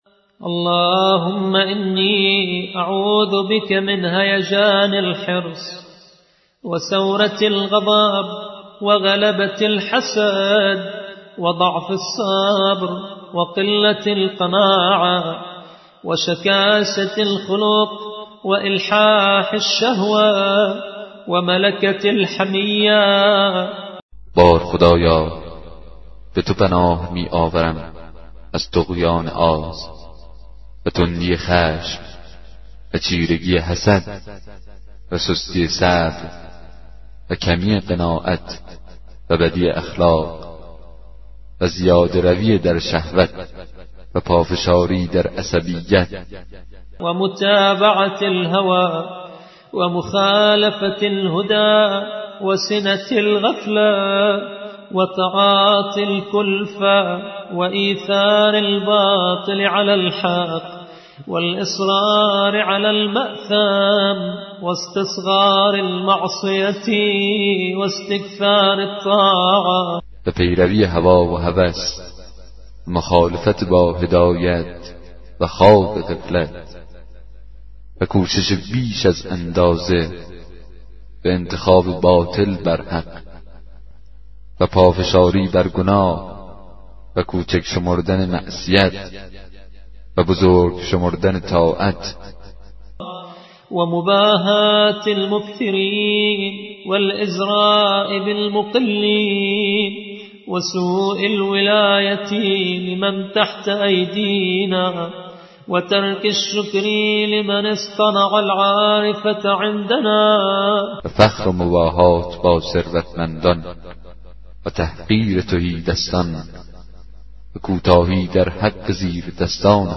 کتاب صوتی دعای 8 صحیفه سجادیه